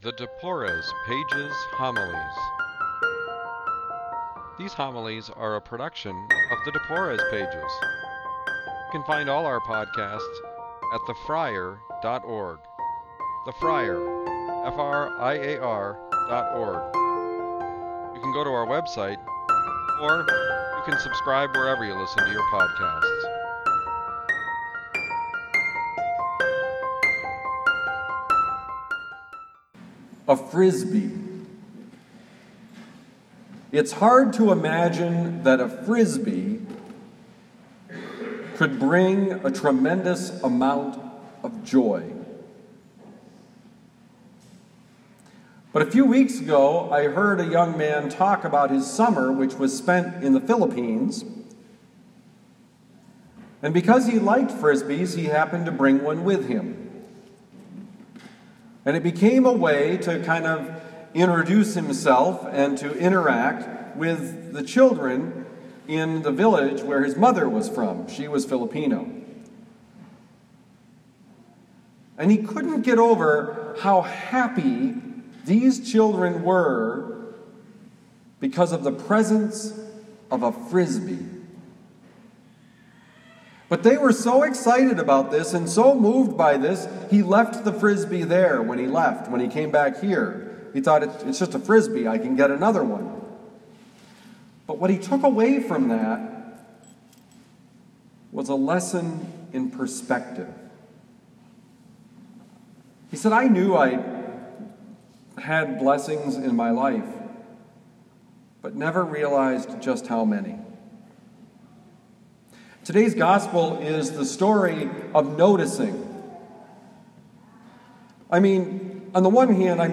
Homily for the 26th Sunday in Ordinary Time, at Our Lady of Lourdes, University City, Missouri, on September 29, 2019.